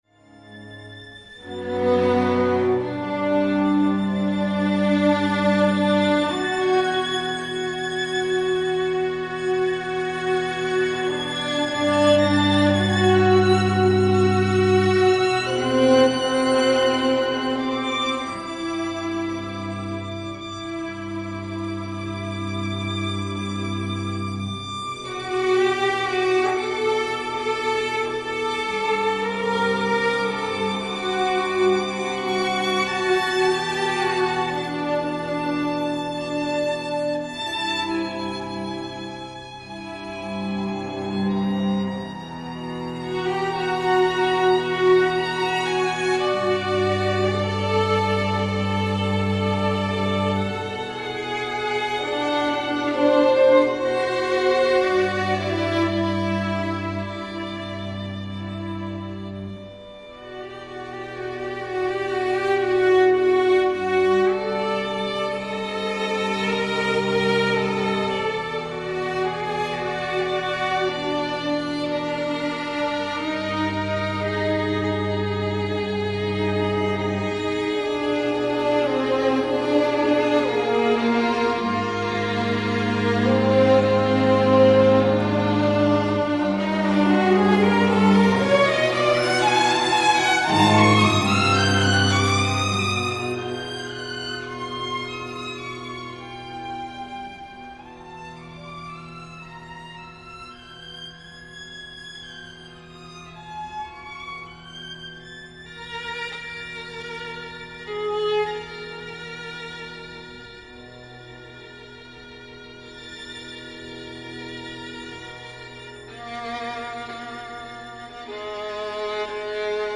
string octet